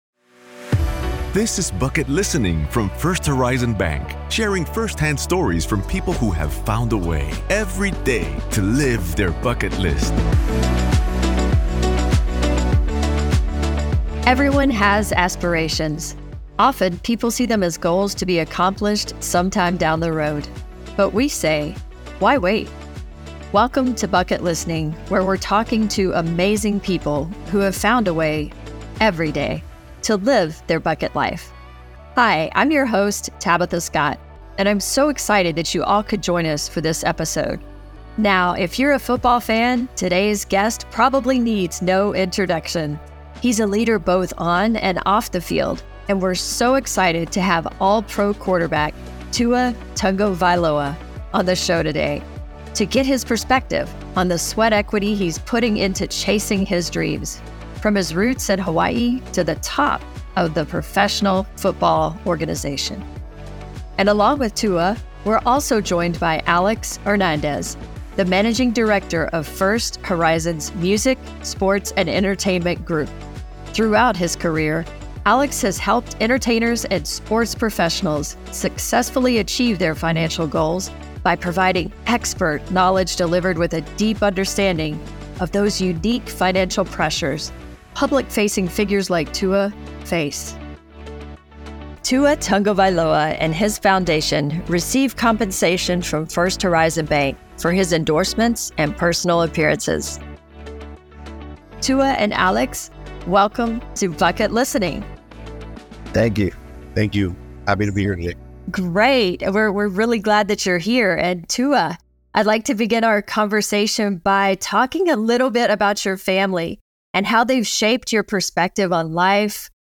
Pro Football Quarterback, Tua Tagovailoa shares his perspective on the sweat equity he’s put into chasing his dreams. From his roots in Hawaii to the top of professional football, Tua is a leader both on and off the field, so this is one conversation you don’t want to miss.